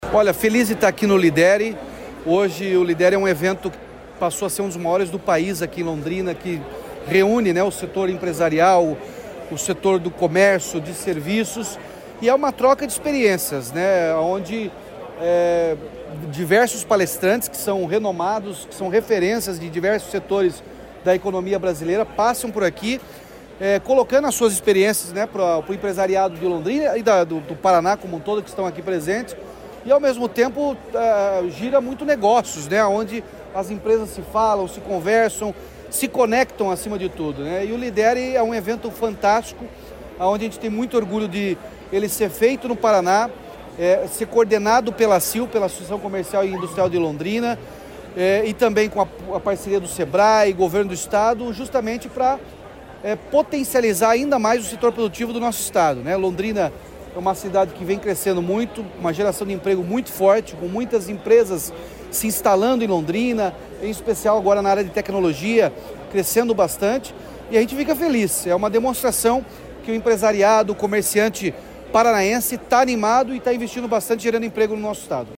Sonora do governador Ratinho Junior sobre a abertura do LiDERE+ em Londrina